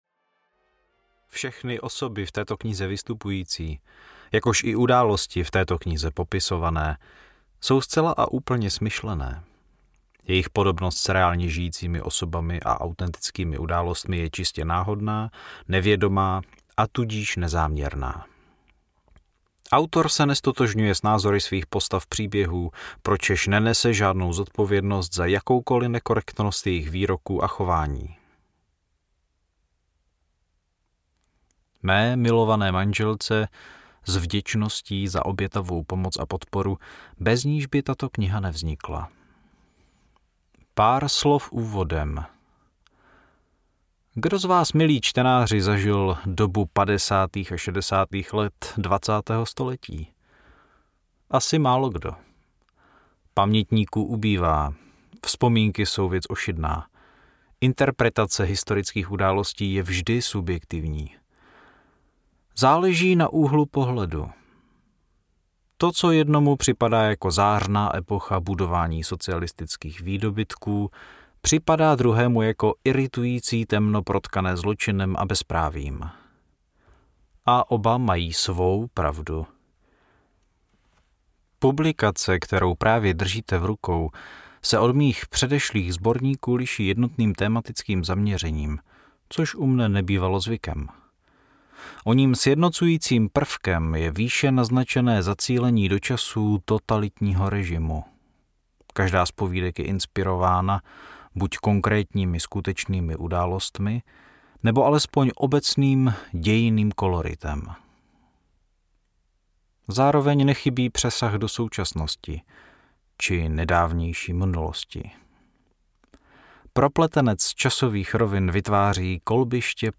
Mám se dobře, ale špatně to snáším audiokniha
Ukázka z knihy